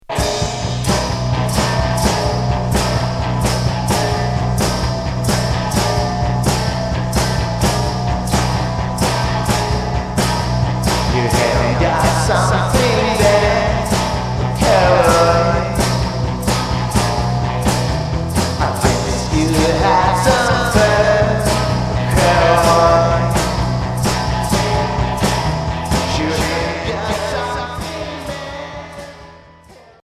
Garage r'n'b Deuxième 45t retour à l'accueil